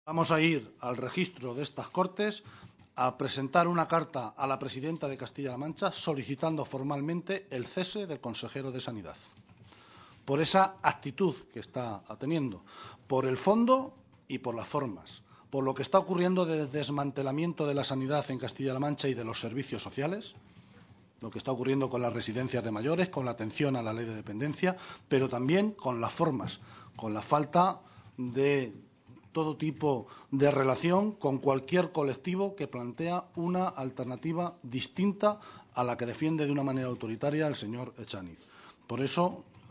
Guijarro y Mora a la entrada de las Cortes regionales